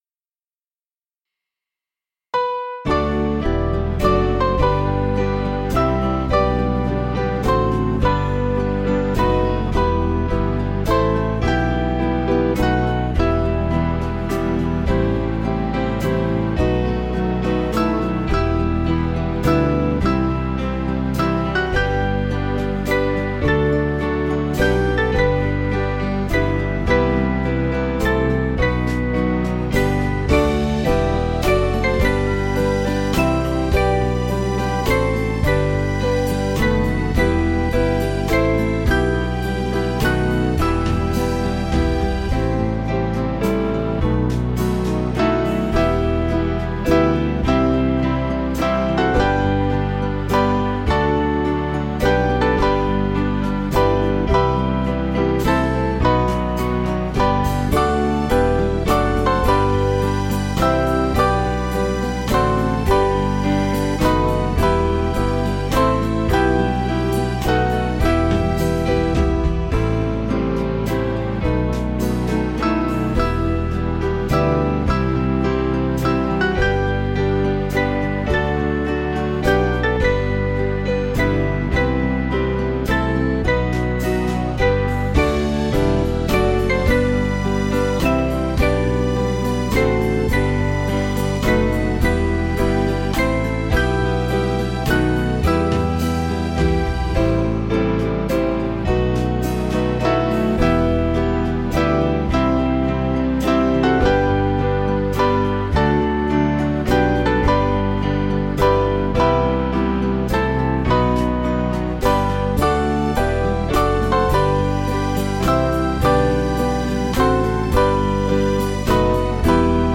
(CM)   5/Em 453.8kb
Small Band